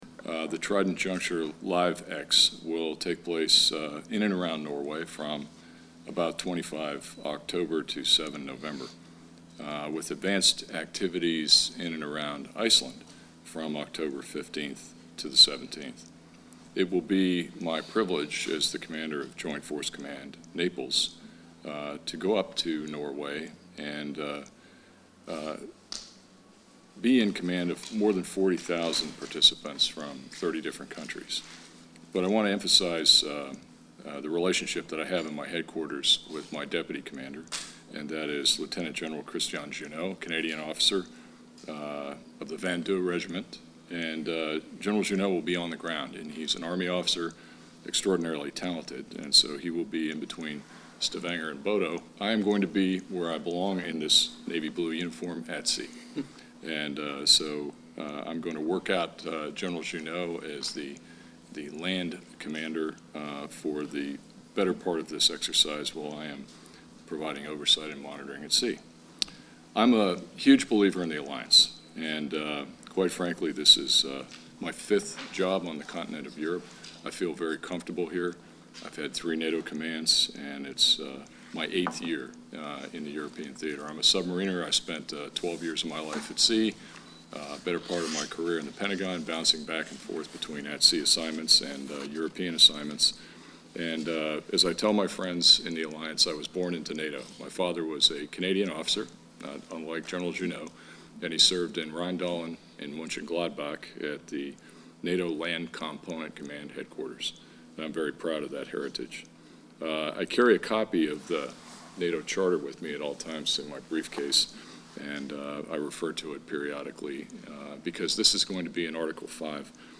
At a press briefing in Brussels today (11 June), Admiral Foggo gave the media a first preview of the exercise together with Vice Admiral Ketil Olsen, Military Representative of Norway to the NATO Military Committee.